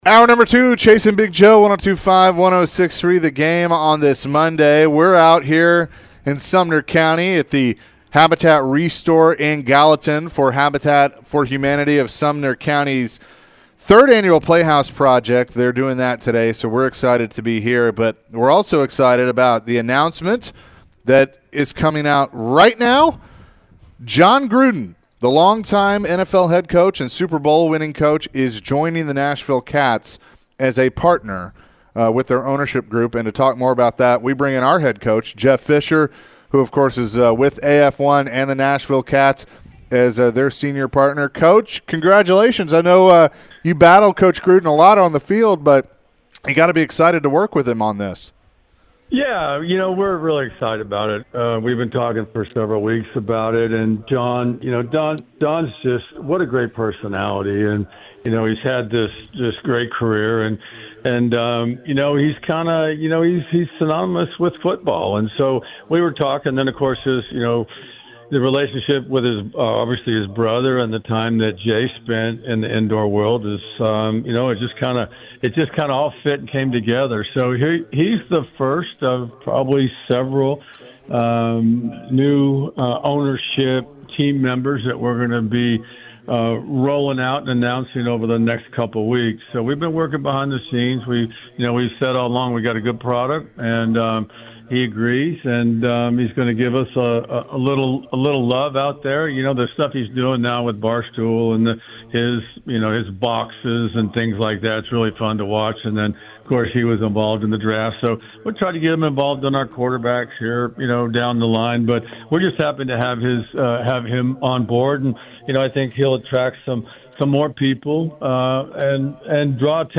Later in the hour the guys answered some calls and texts.